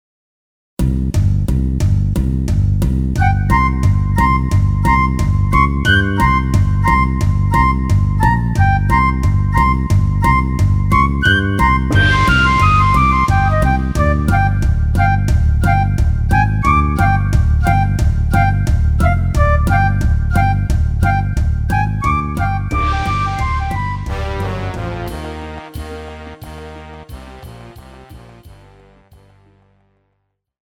Žánr: Pop
MP3 ukázka